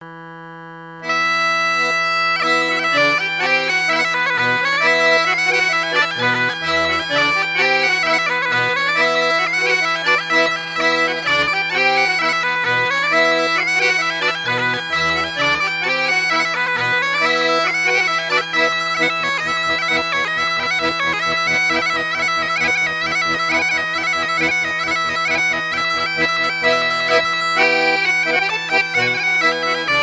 danse : ronde : grand'danse
Pièce musicale éditée